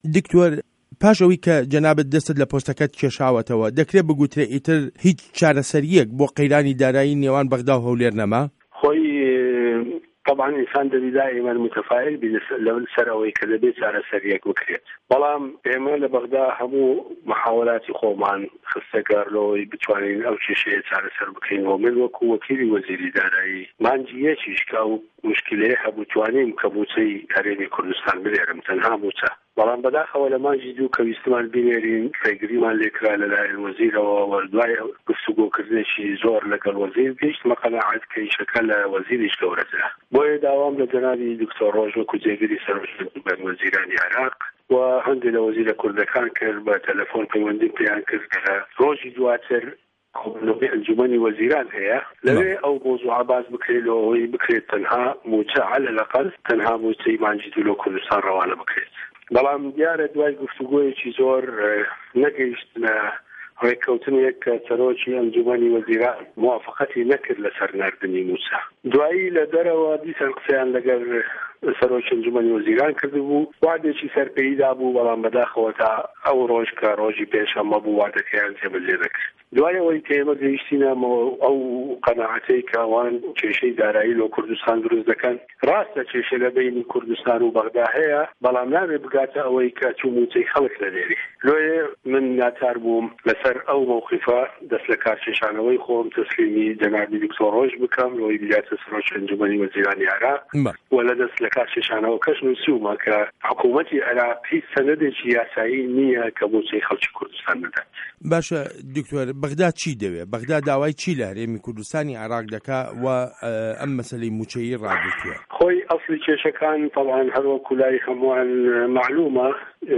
وتووێژ له‌گه‌ڵ دکتۆر فازیل نه‌بی